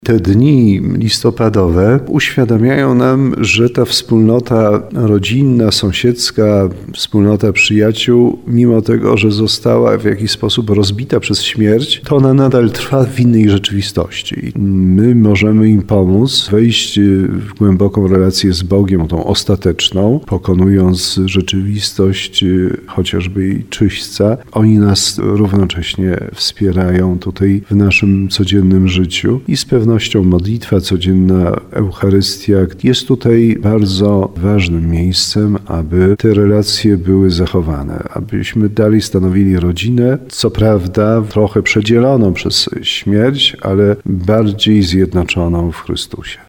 Biskup tarnowski Andrzej Jeż podkreśla wartość modlitwy za zmarłych.